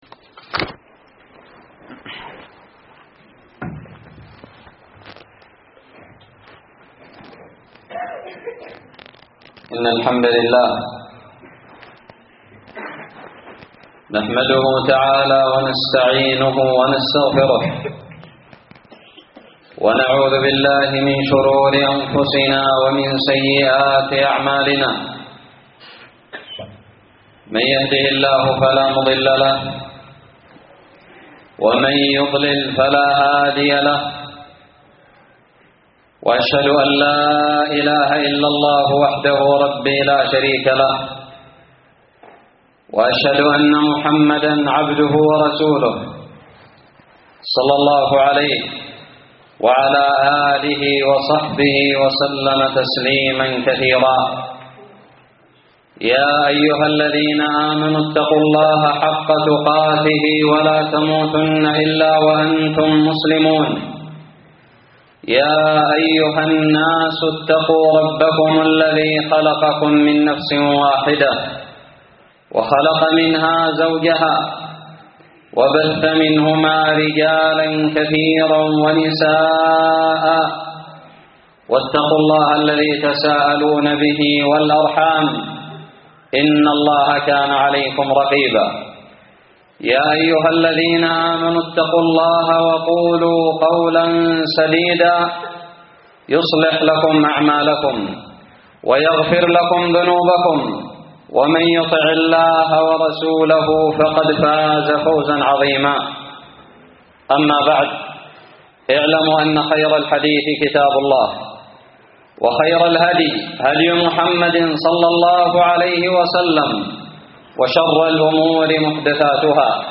خطب الجمعة
ألقيت بدار الحديث السلفية للعلوم الشرعية بالضالع في 20 ربيع الأول 1439هــ